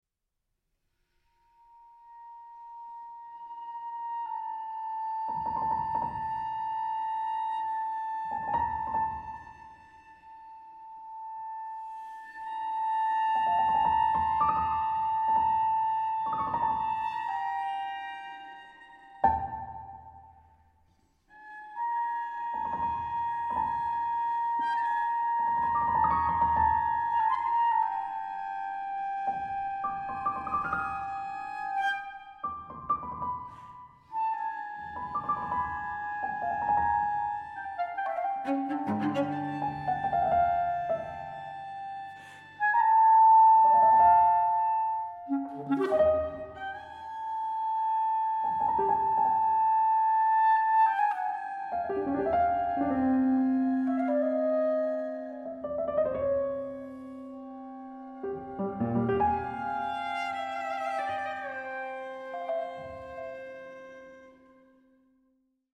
clarinet
cello